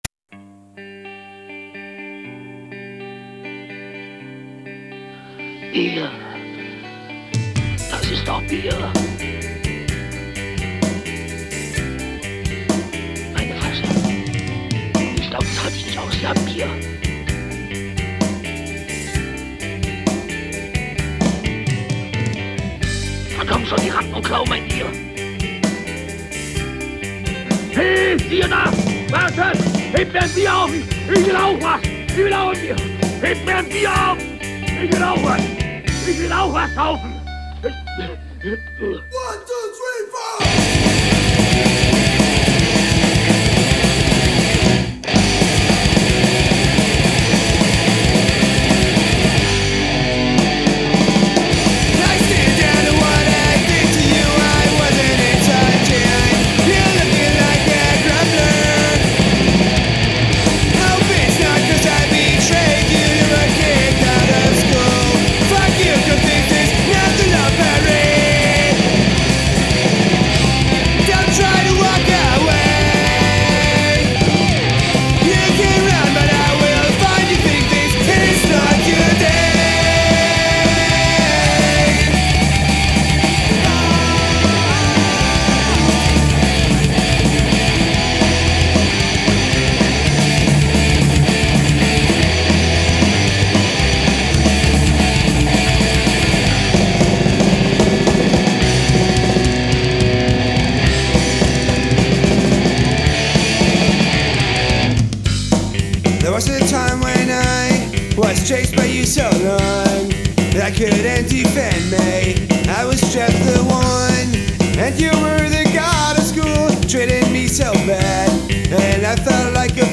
Unveröffentl. Demo (1999)